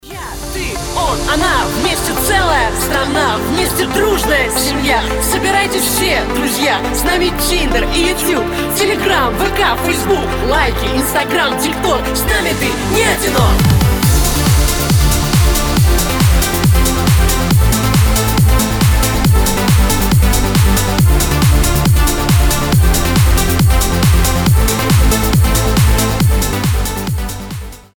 • Качество: 320, Stereo
позитивные
нарастающие
progressive house